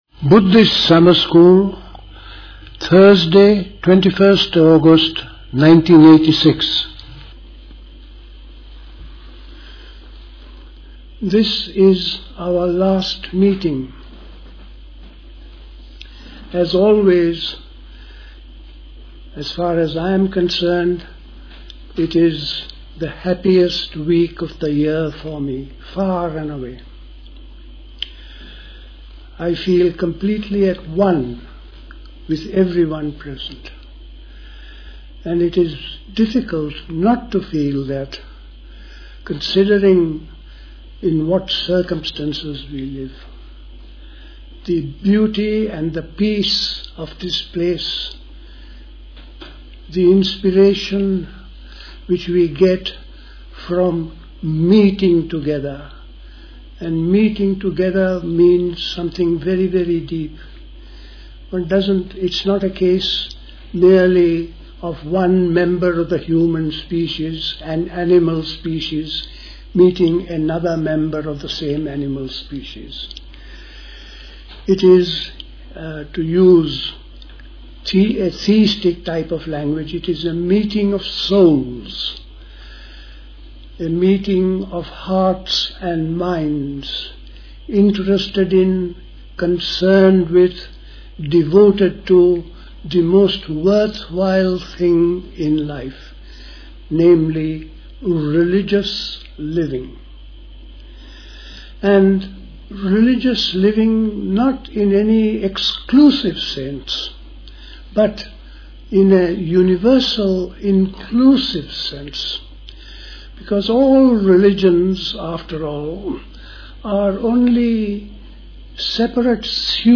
High Leigh Conference Centre, Hoddesdon, Hertfordshire
The Buddhist Society Summer School Talks